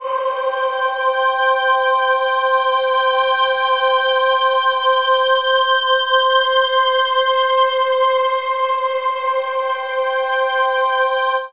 36af01pad-cM.wav